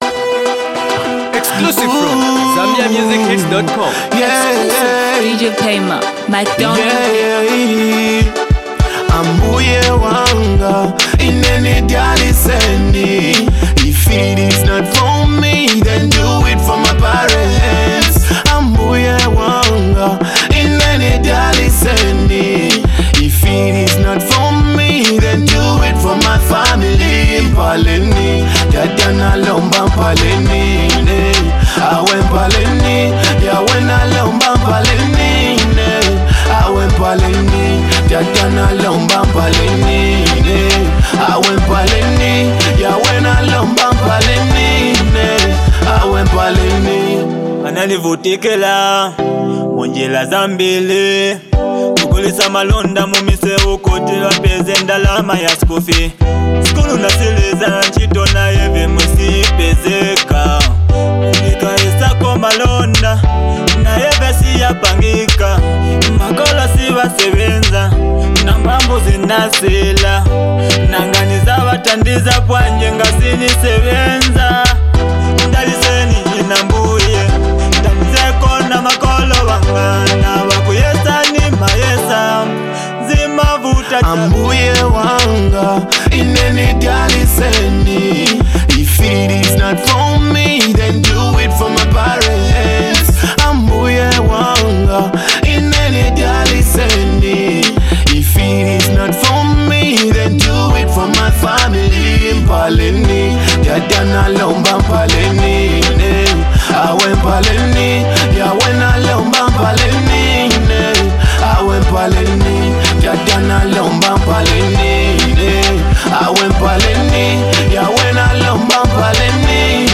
Zambian Talented Singer
Educative/Gospel fused banger